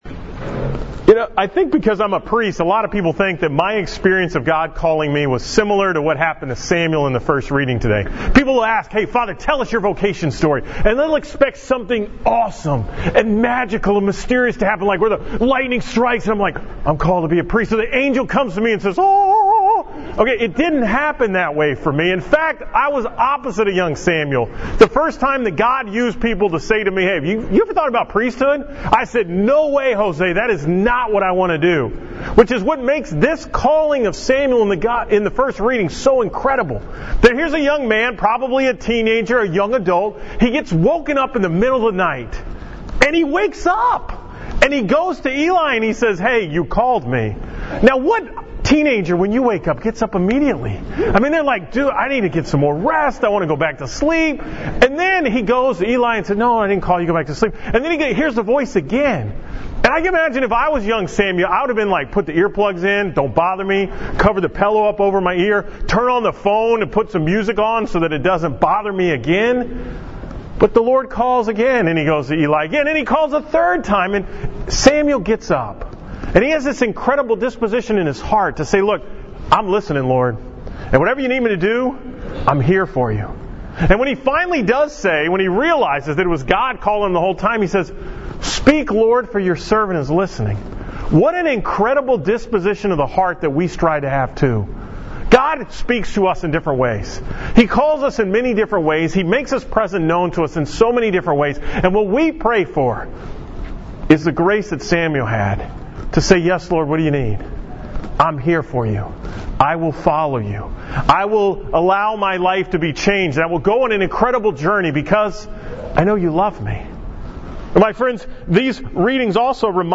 From Mass at St. Theresa in the Park on the 2nd Sunday of Ordinary Time